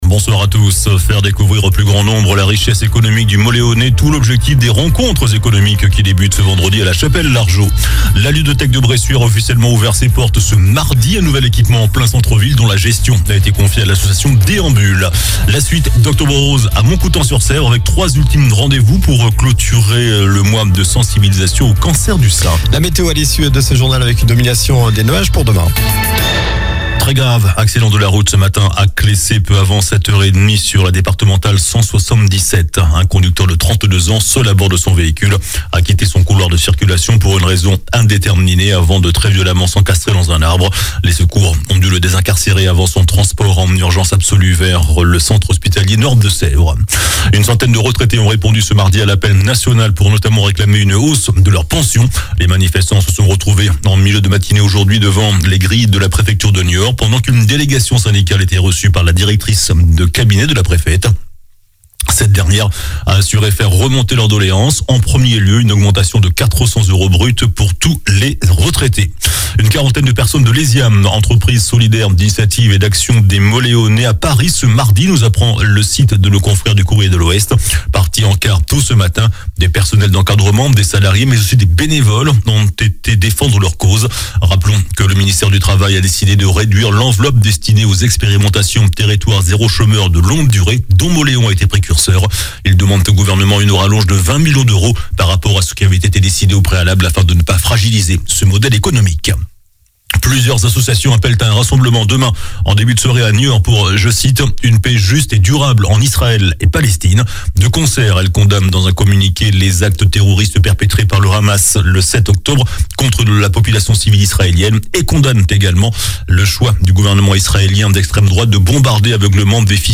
JOURNAL DU MARDI 24 OCTOBRE ( SOIR )